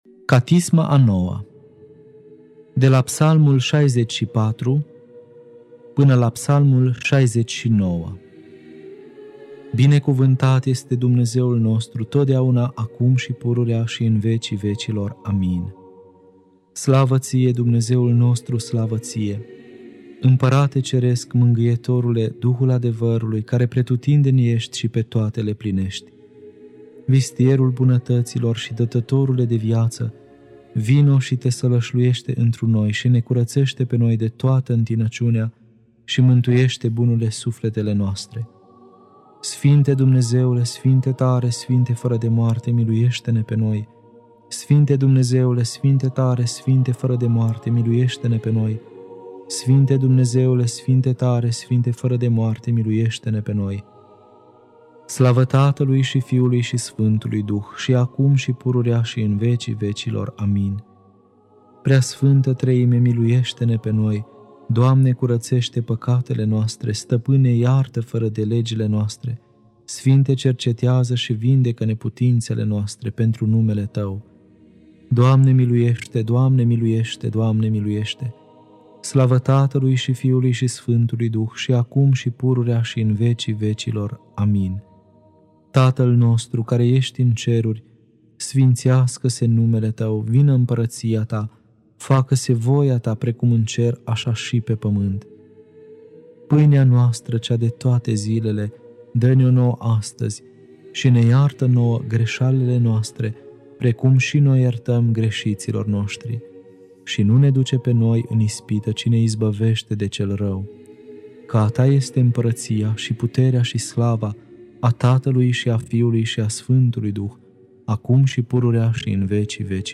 Catisma a IX-a (Psalmii 64-69) Lectura